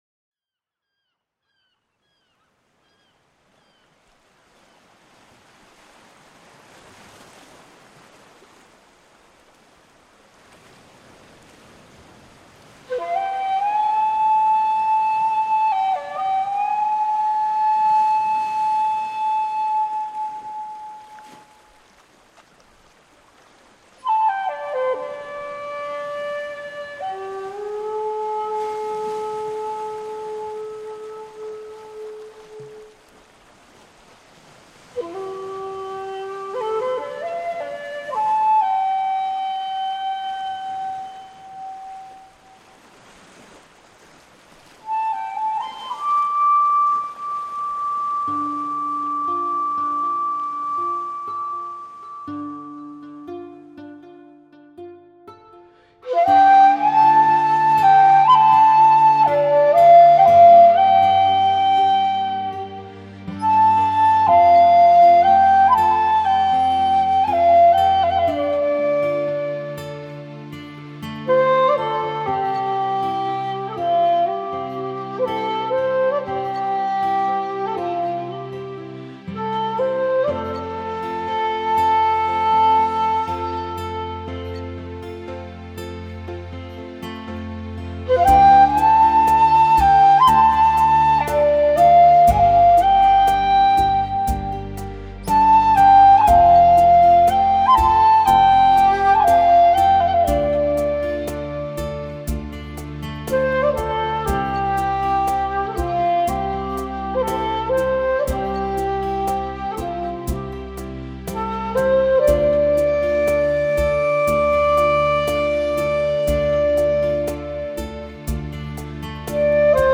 "Тропа Ангелов" - Февраль 2011 - Музыка из мультика… Сякухати + AKAI EWI USB + GarageBand + MacBook Pro